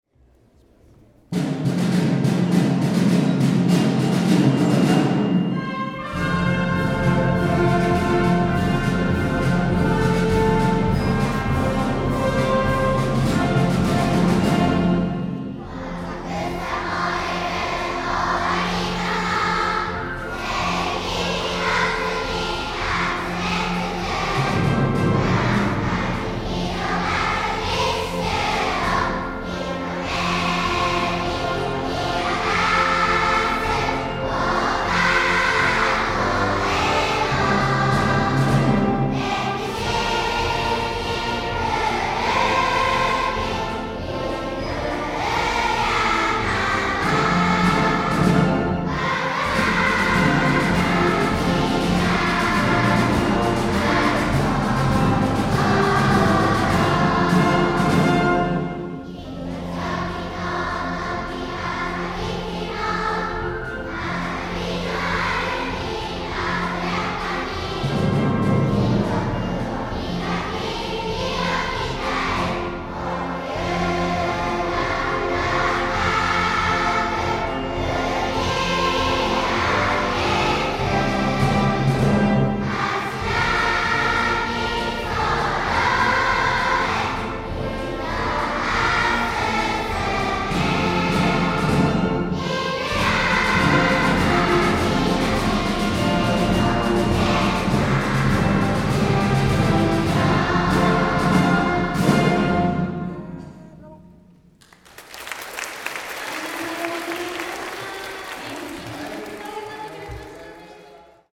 オーケストラをバックに子どもたちが元気よく校歌を歌っています。